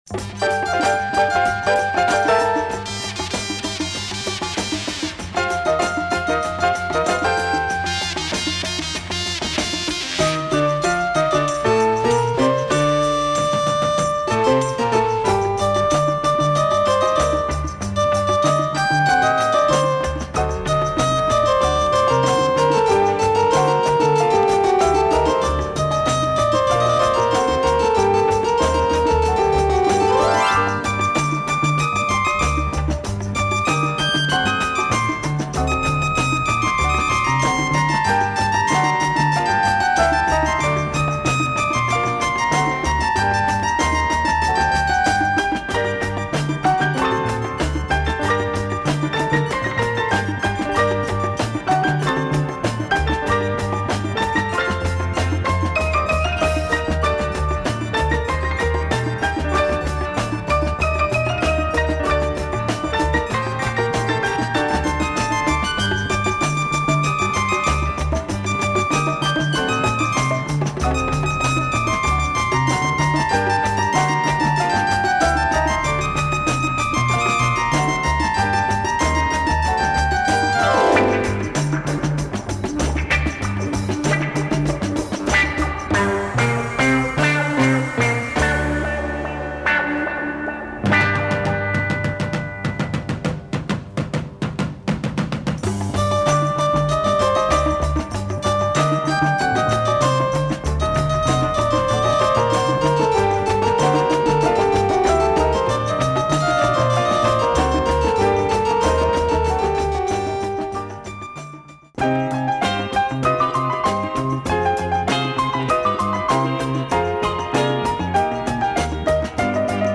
Very rare Armenian / Lebanese jazz.
Superb oriental sound from the early 70's, superb condition.